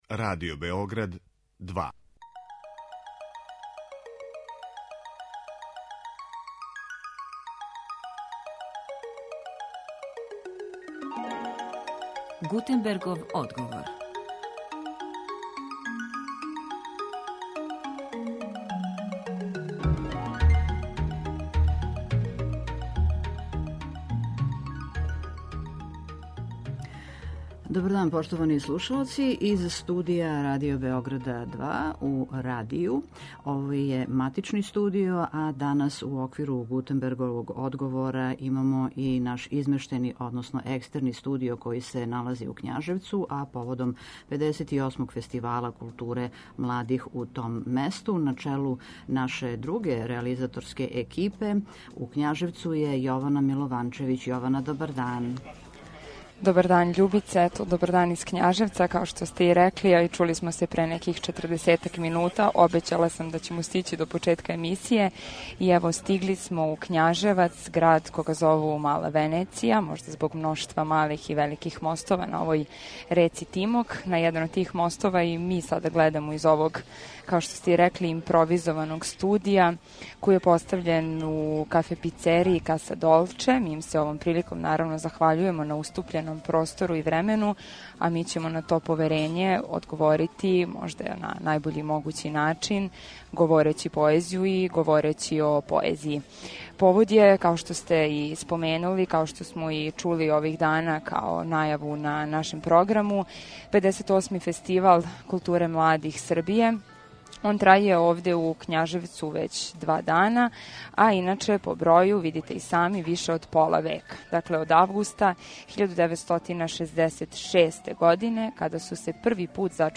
Емисију Гутенбергов одговор данас реализујемо уживо из Књажевца, где је у току 58. Фестивал културе младих Србије.